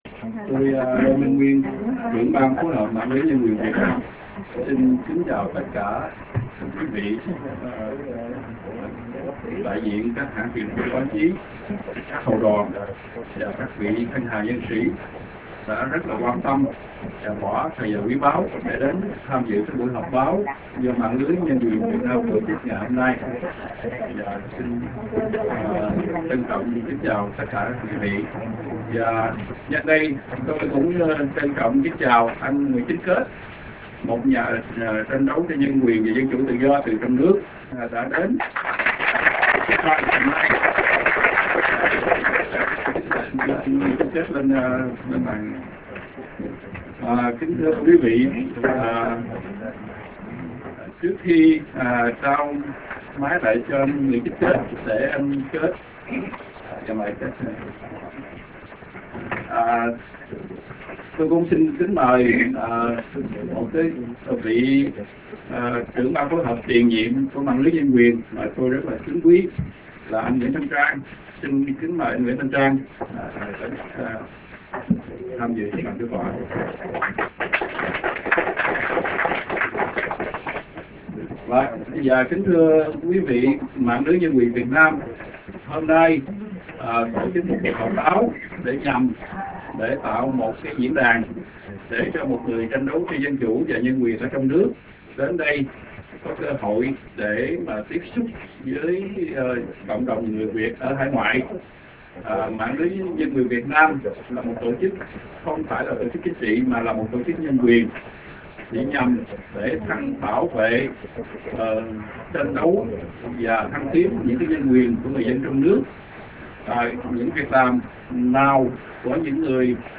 Buổi họp b�o được trực tiếp truyền h�nh, cũng như trực tiếp truyền thanh qua c�c hệ thống Paltalk. Buổi họp b�o đ� diễn ra trong bầu kh�ng kh� th�n mật v� cởi mở.
Suốt buổi họp b�o, rất nhiều lần cả hội trường đ� vang dội tiếng vỗ tay t�n thưởng �ng.